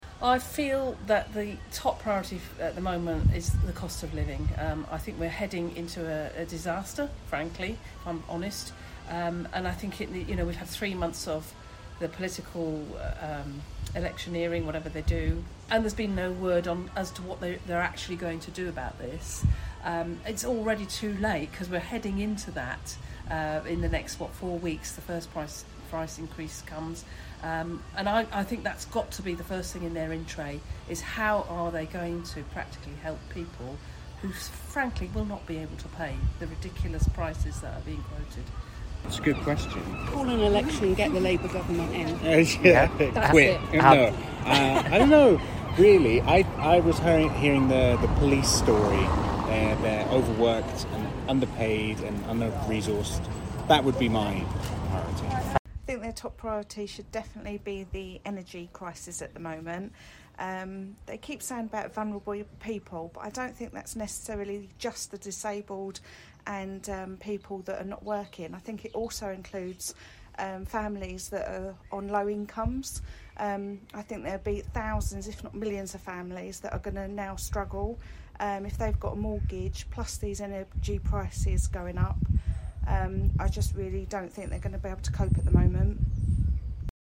LISTEN: We asked shoppers what the new PM's top priority should be - 05/09/22